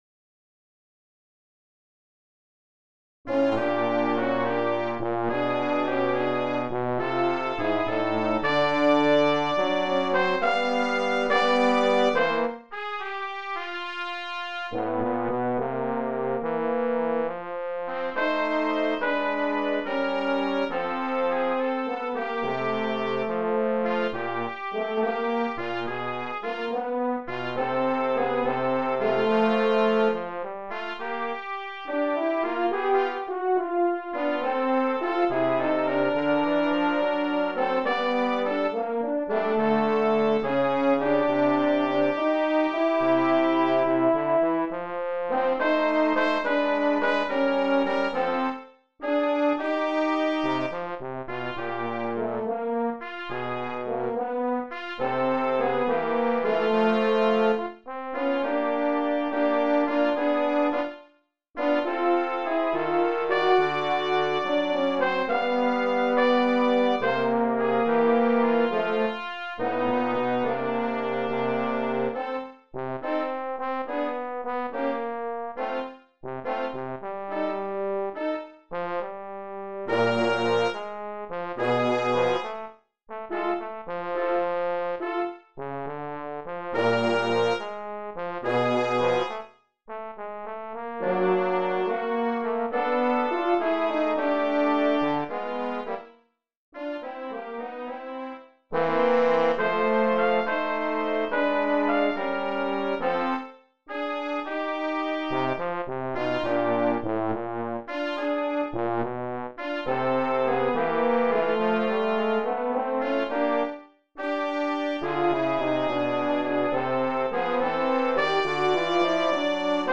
Brass Trio
(in Bb)